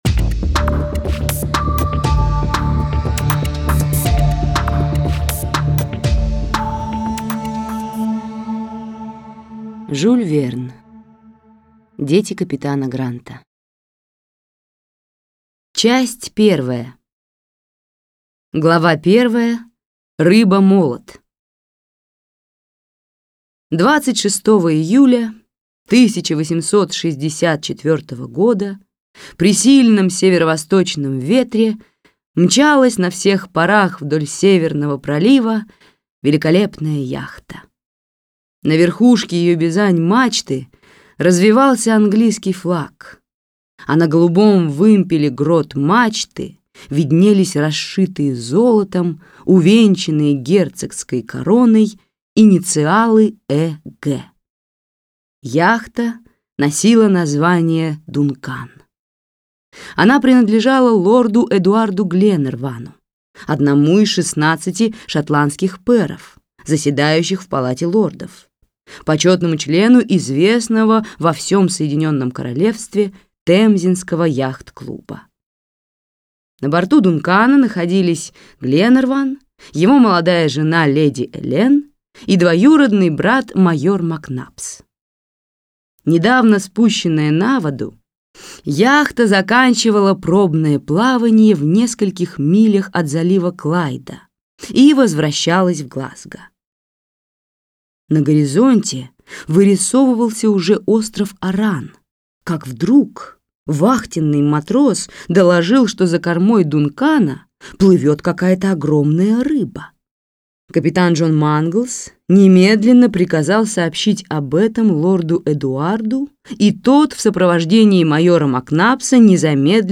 Аудиокнига Дети капитана Гранта | Библиотека аудиокниг